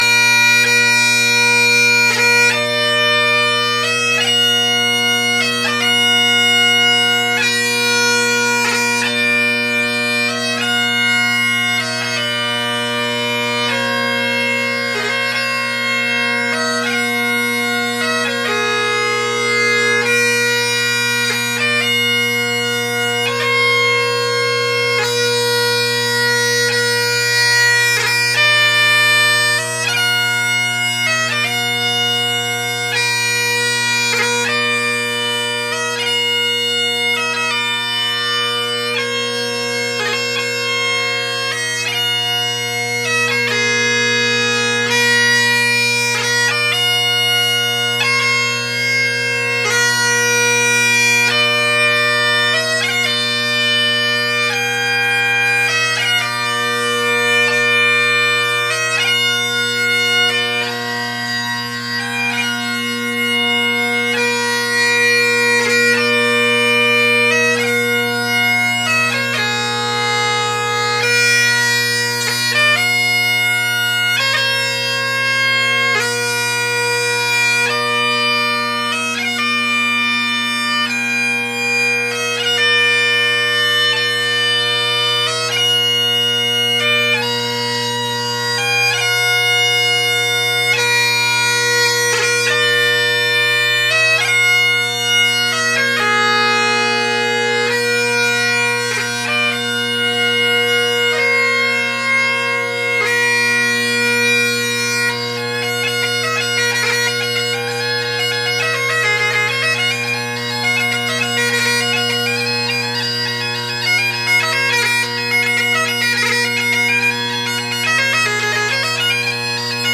Great Highland Bagpipe Solo
Tuning, or the playing, isn’t great or perfect, but good enough for 20 minutes out of the shipping box!
Pipes with Kinnaird drone reeds: